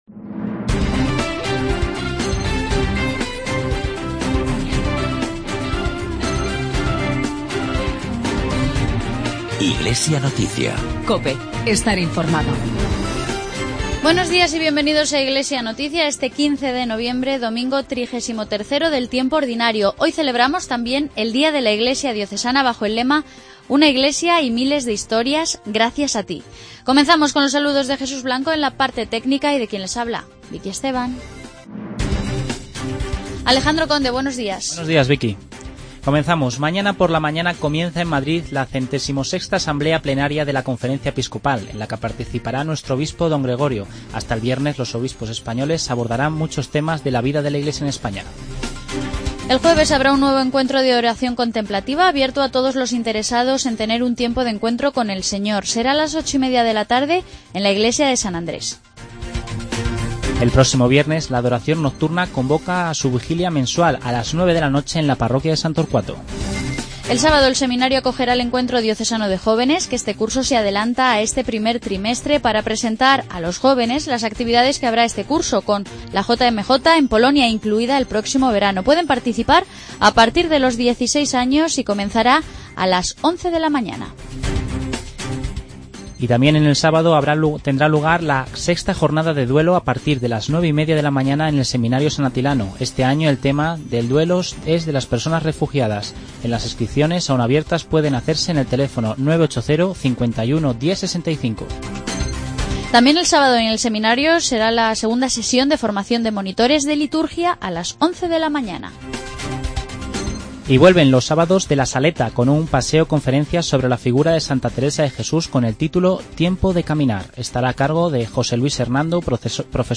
Informativo diocesano.